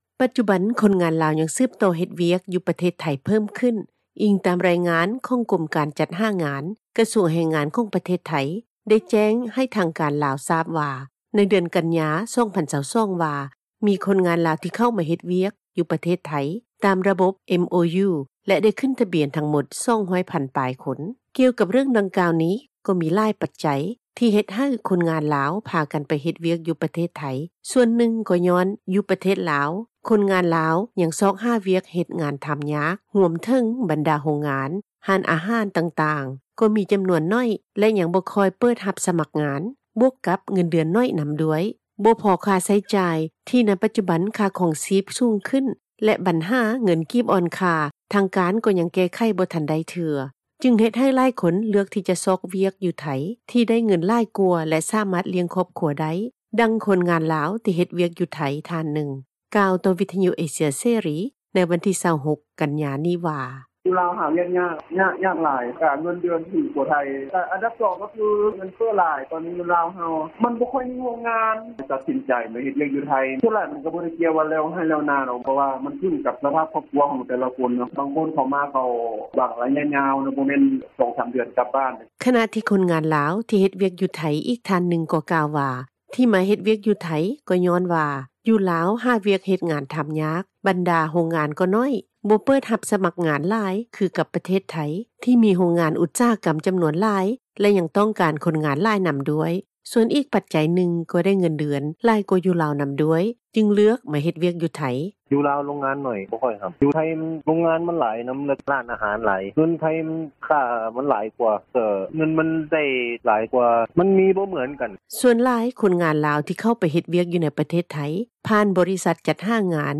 ດັ່ງຄົນລາວ ທີ່ເຮັດວຽກຢູ່ໄທຍ ທ່ານນຶ່ງ ກ່າວຕໍ່ວິທຍຸ ເອເຊັຽເສຣີ ໃນວັນທີ 26 ກັນຍານີ້ວ່າ:
ດັ່ງເຈົ້າໜ້າທີ່ ບໍຣິສັດຈັດຫາງານ ແຫ່ງນຶ່ງກ່າວວ່າ: